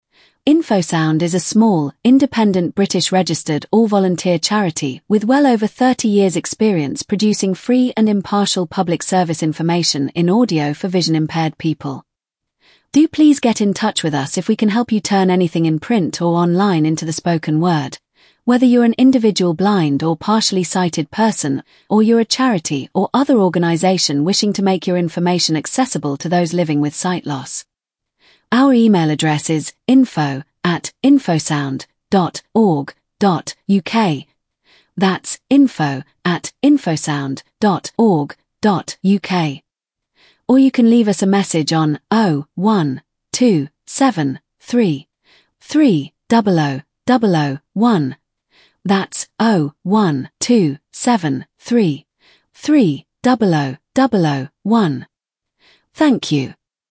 Turning Text into Speech